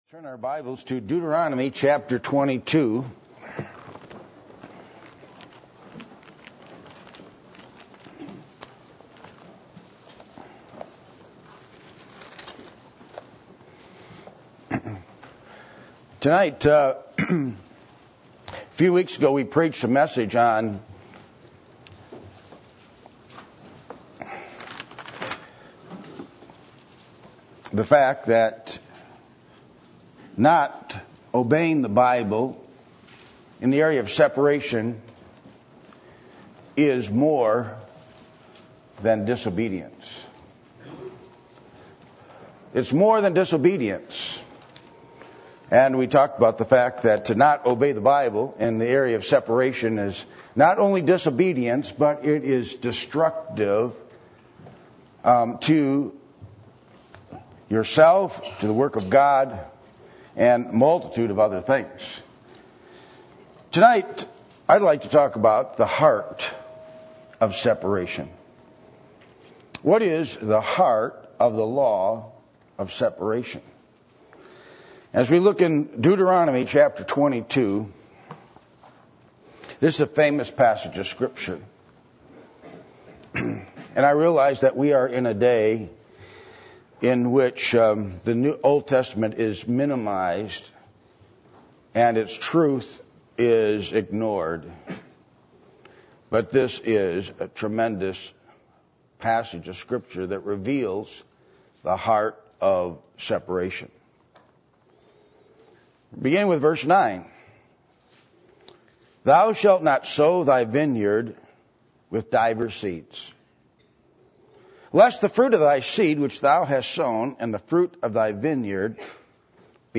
Passage: Deuteronomy 22:9-12 Service Type: Sunday Morning